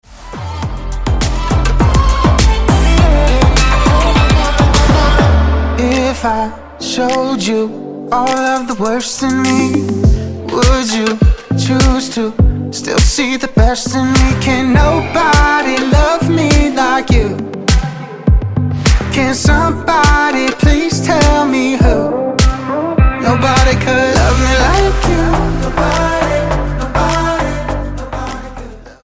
Pop
Nashville-based band
Style: Dance/Electronic